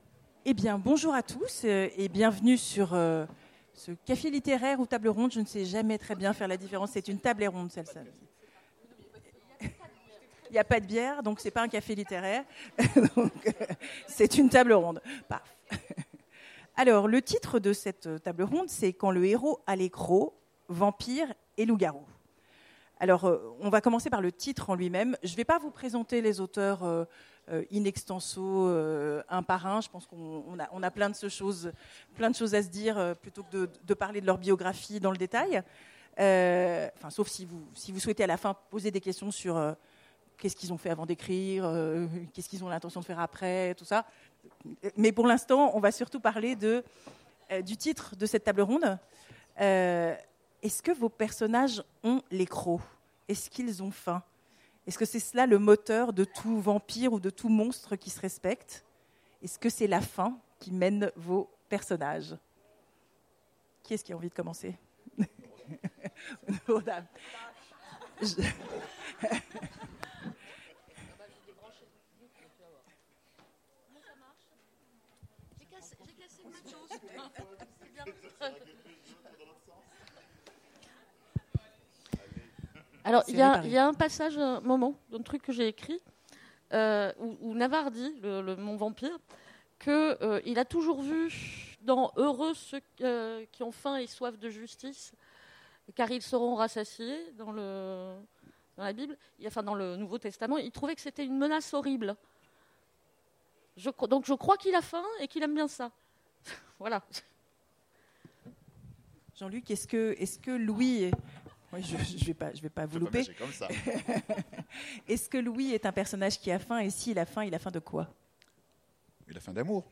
Imaginales 2016 : Conférence Quand le héros a les crocs…